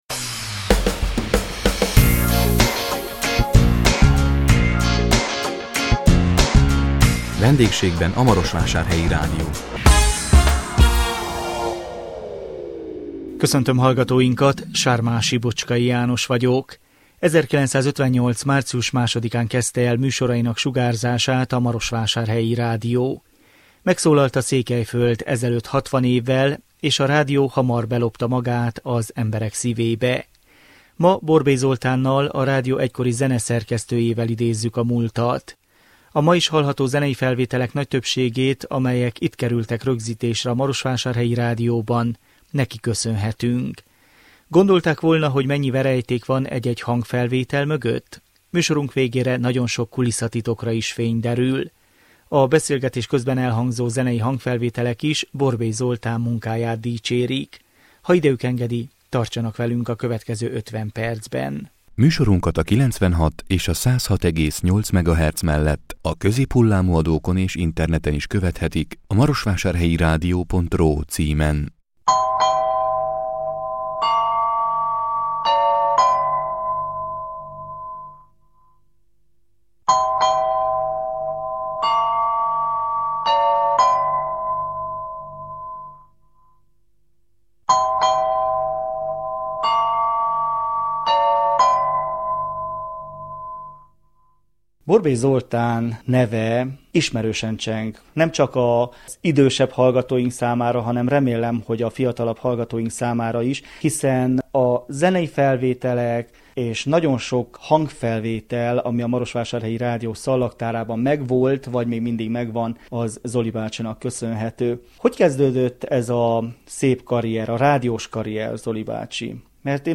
A 2018 február 15-én jelentkező Vendégségben a Marosvásárhelyi Rádió című műsorunk különkiadásában a 60 éves Marosvásárhelyi Rádióról beszélgettünk. 1958. március 2-án kezdte el műsorainak sugárzását a Marosvásárhelyi Rádió.